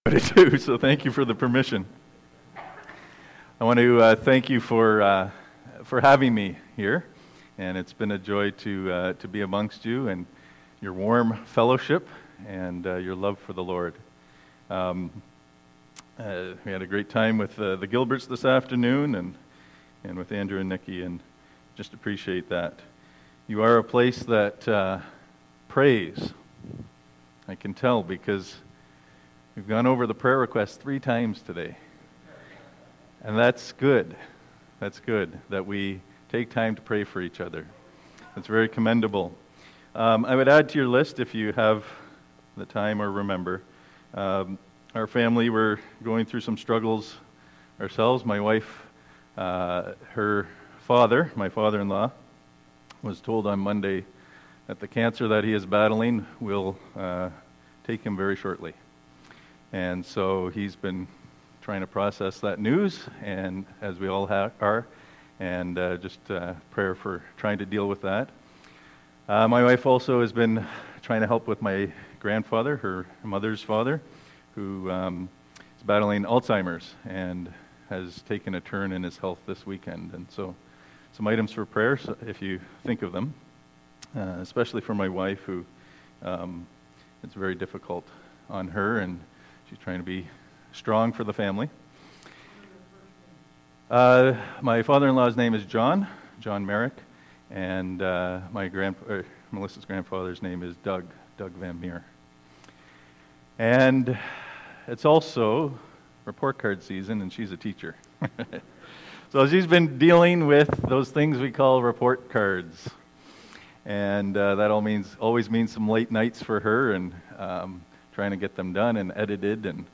Bible Text: Acts 3, Eph. 3, Rev. 2,3 | Preacher: